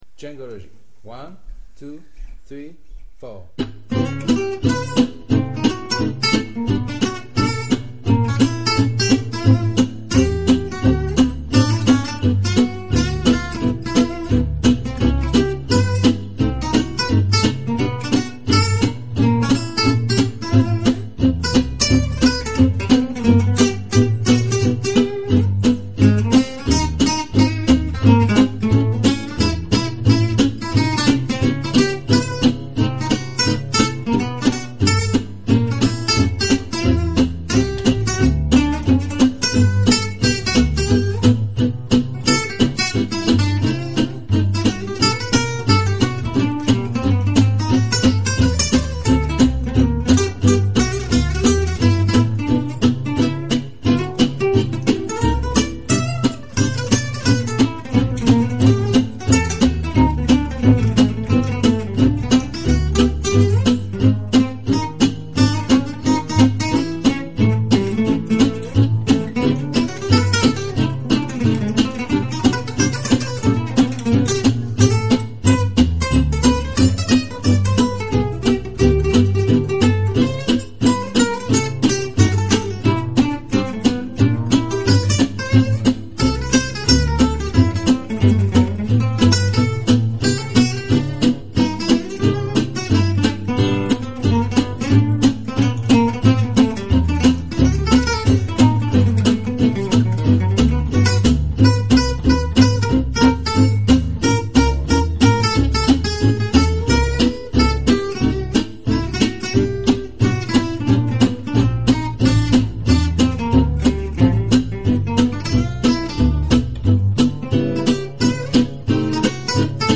Au mixage, baisse un peu la pompe sinon ... rien à jeter !